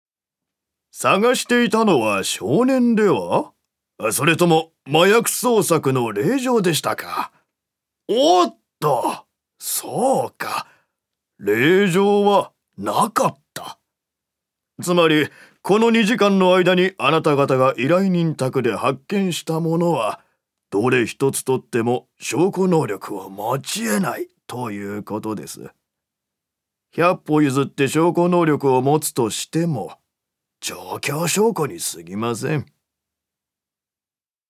所属：男性タレント
セリフ１